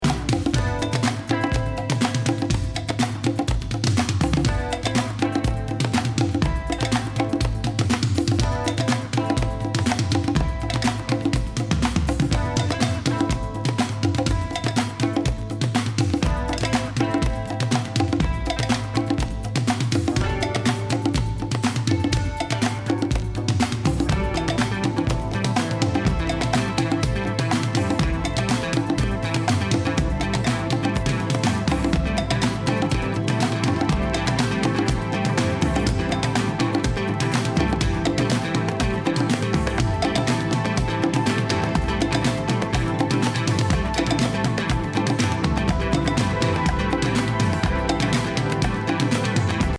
・エレクトロ・ディスコ